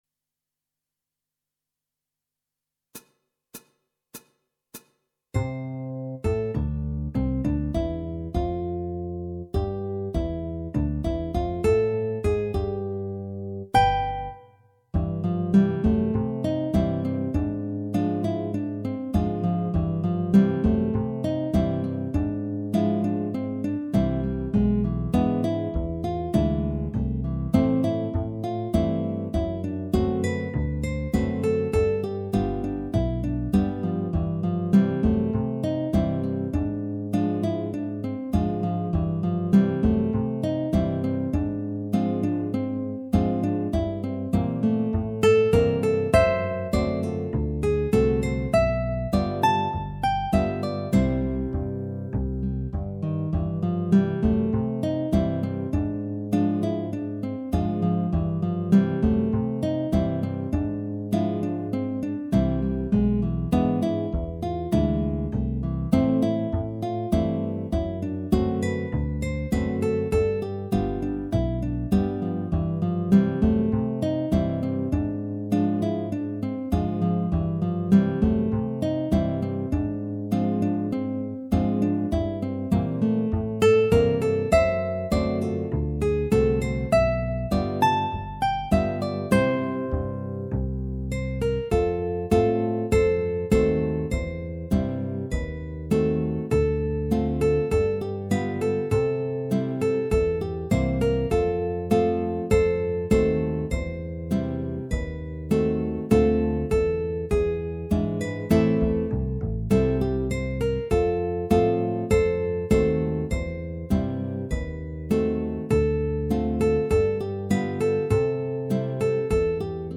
Guitar Quartet
minus Guitar 1